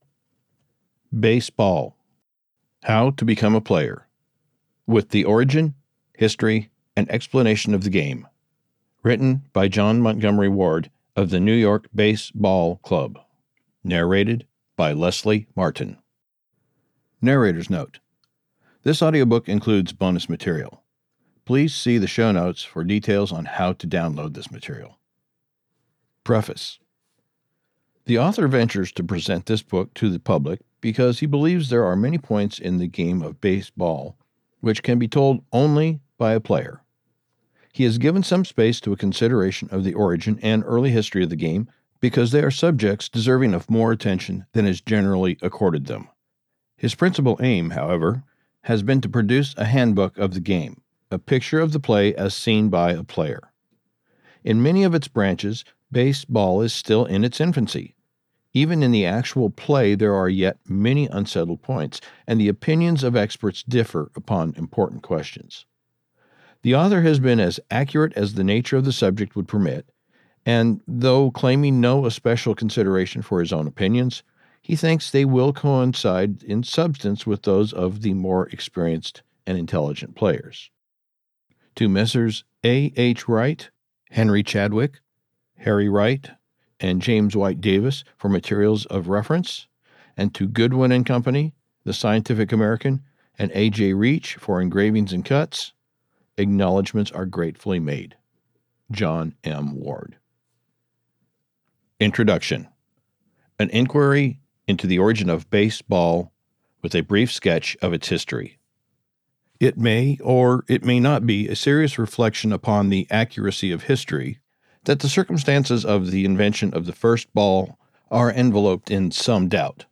Audiobook production Copyright 2025, by GreatLand Media.